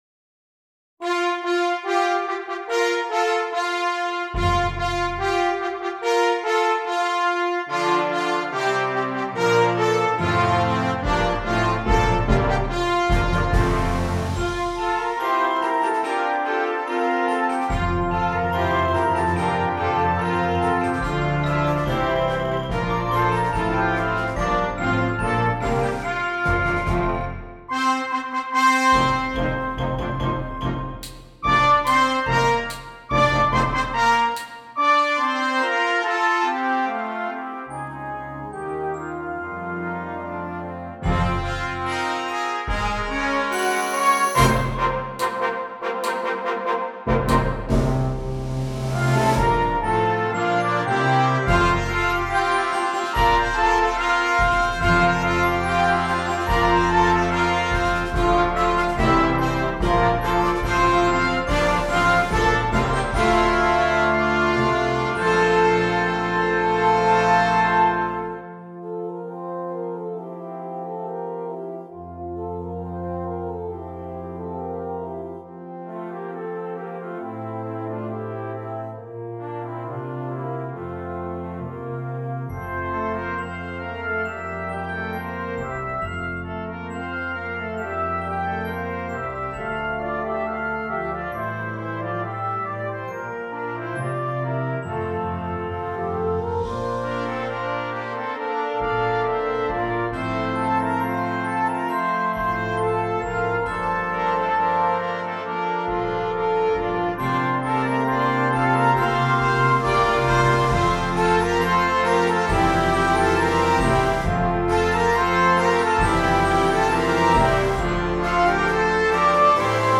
Voicing: Brass Choir